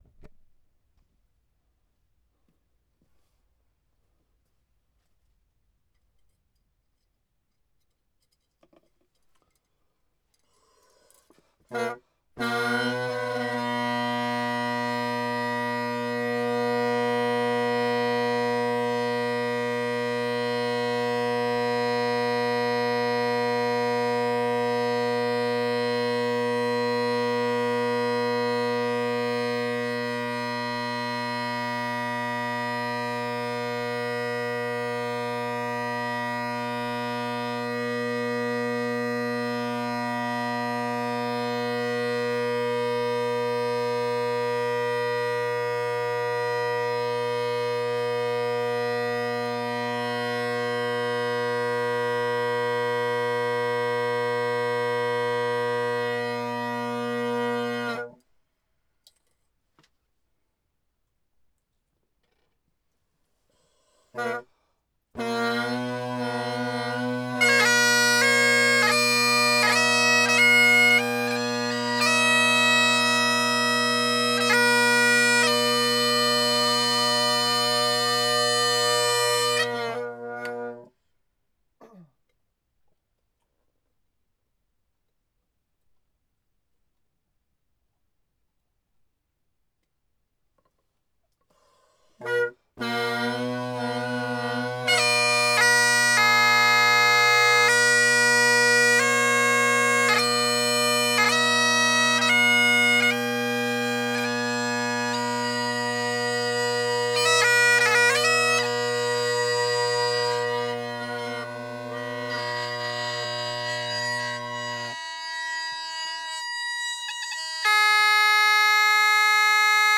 3. I know a couple of people working on various electronic bagpipe projects so here is a recording of drones and chanter separate. I played a bit, got them all in tune, then plugged the chanter with that Kinnaird plug thingie and played the drones and then popped off the drones and played the chanter, all without stopping. Take samples toward the end of each note on the chanter because I took a breath at each switch and I’m not the steadiest blower, except for E as it’s a tiny bit flat, take the front end of that note (my bad). It’s a big file and unedited, have fun!
Drones then chanter .wav file
In case you’re wondering, they’re my Gellaitry’s with Colin Kyo chanter.